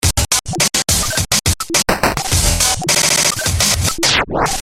Tag: 105 bpm Glitch Loops Drum Loops 787.67 KB wav Key : Unknown